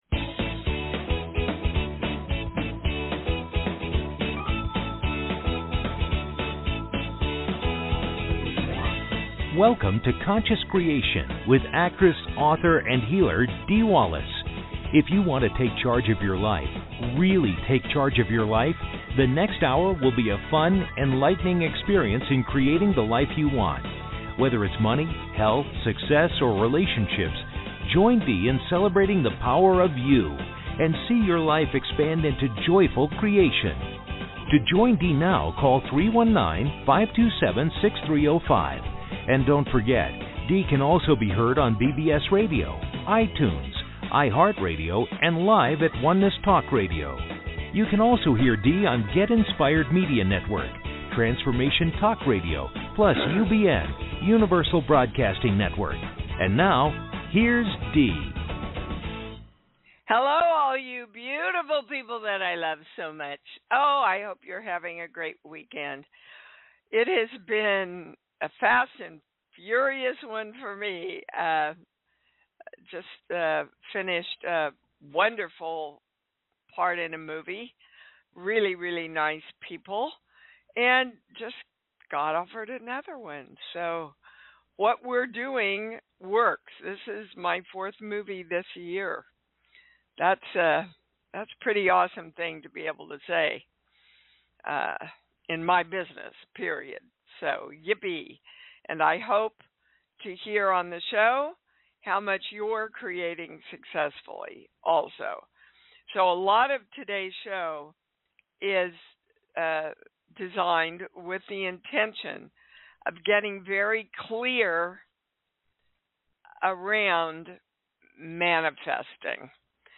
Talk Show Episode, Audio Podcast, Conscious Creating and with Dee Wallace on , show guests , about Conscious Creation with Dee Wallace, categorized as Health & Lifestyle,Paranormal,Philosophy,Emotional Health and Freedom,Personal Development,Self Help,Society and Culture,Spiritual,Psychic & Intuitive